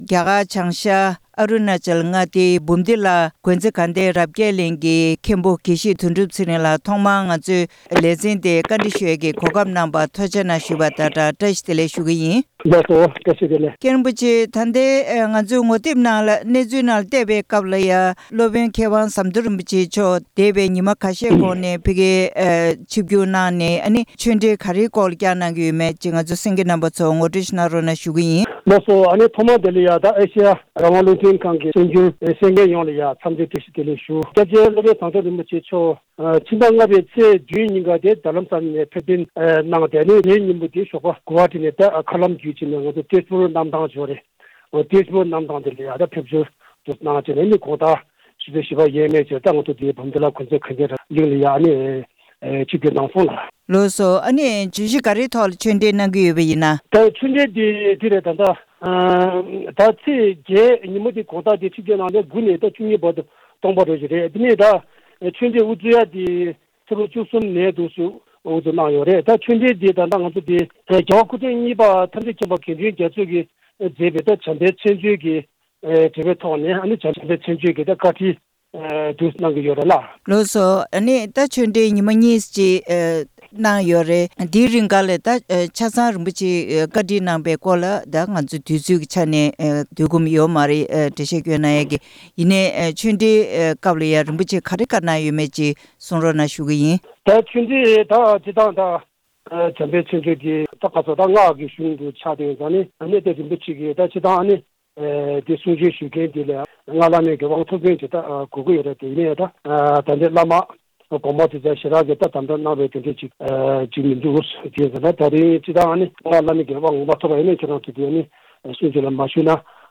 གནས་འདྲི་ཞུས་པ་ཞིག་ལ་གསན་རོགས།